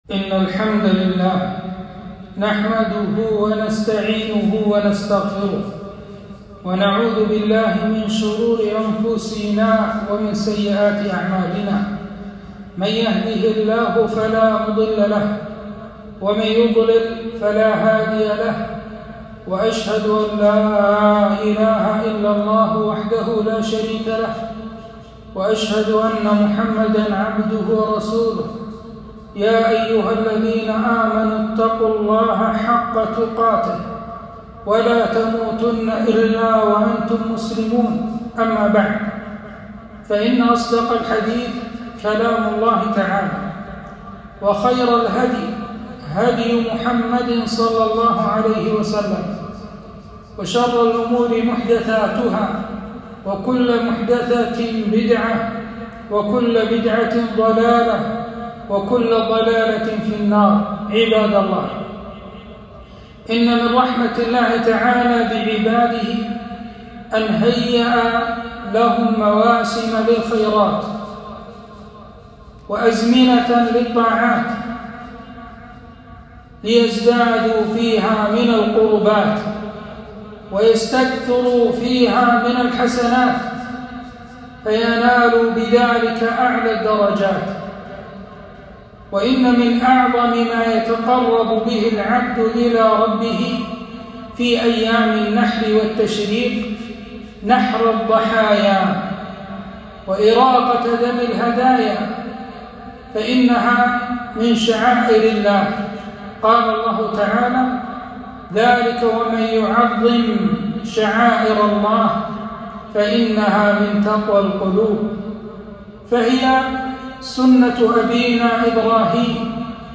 خطبة - أحكام الأضحية والذكاة